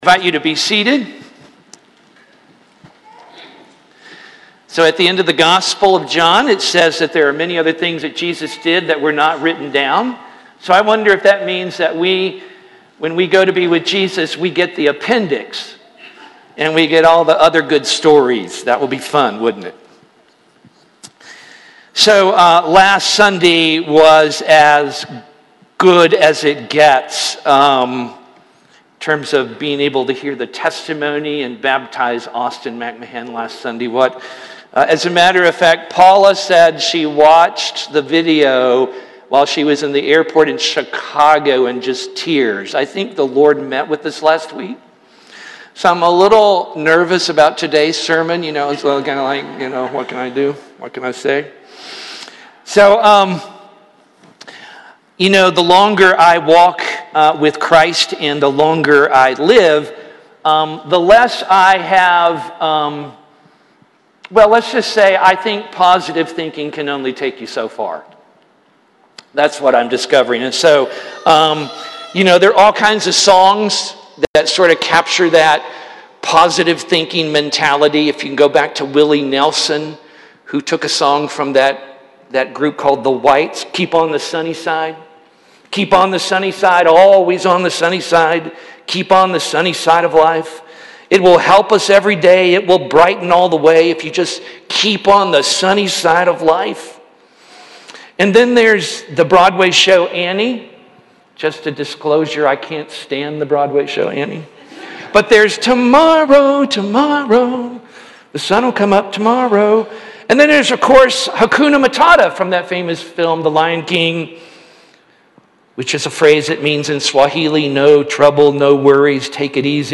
Posted on Apr 19, 2023 in Sermons, Worship |